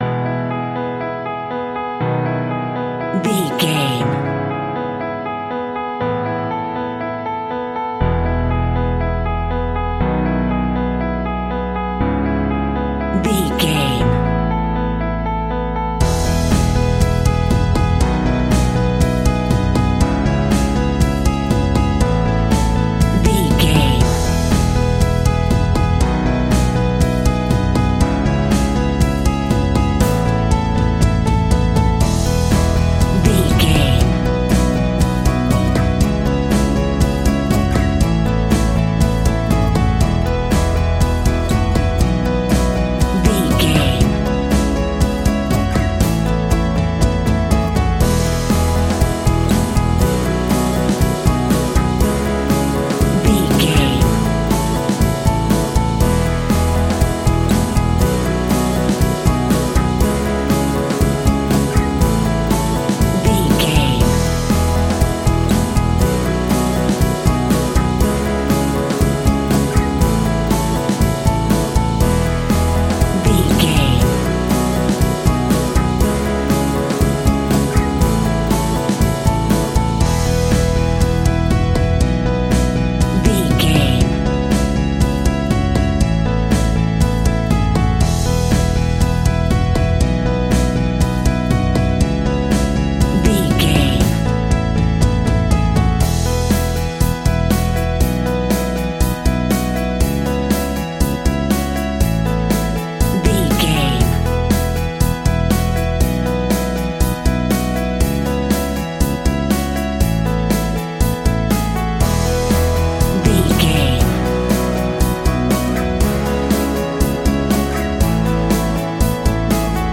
Uplifting
Ionian/Major
pop rock
indie pop
energetic
motivational
cheesy
instrumentals
guitars
bass
drums
piano
organ